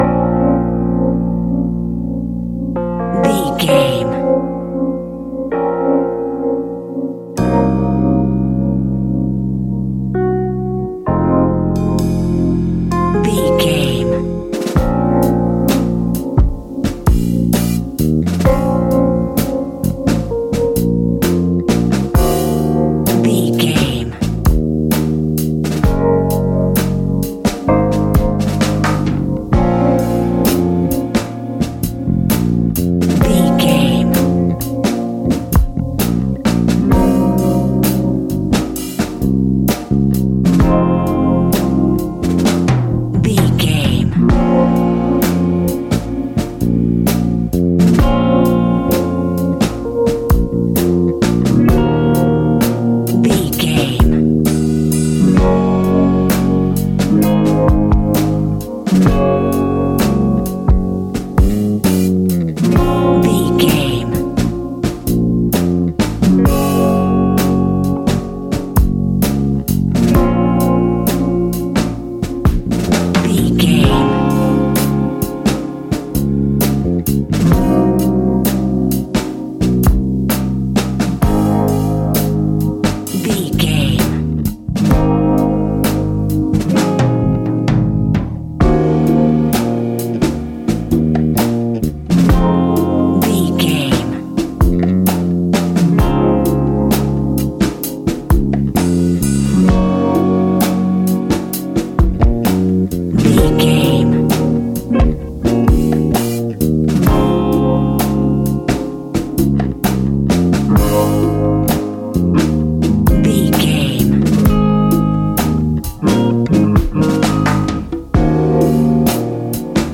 Epic / Action
Uplifting
Ionian/Major
A♯
hip hop